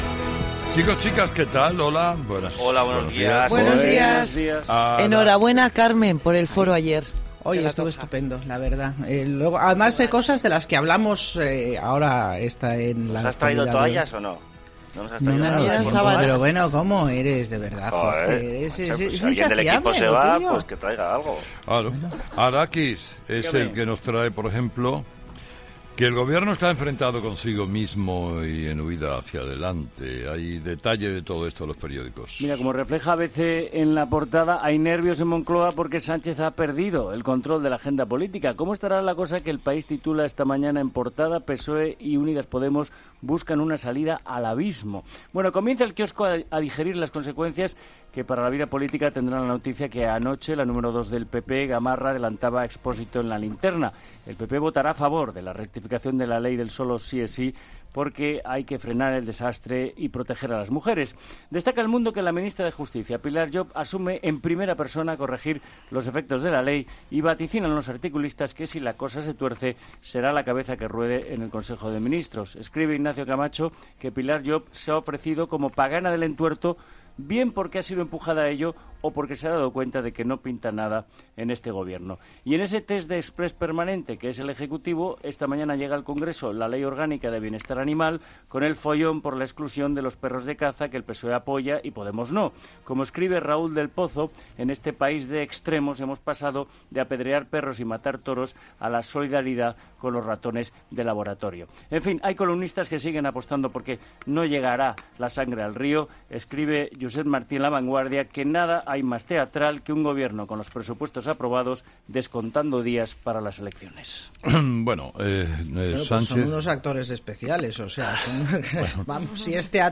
Los tertulianos de 'Herrera en COPE' opinan sobre la cesta de la compra de Nadia Calviño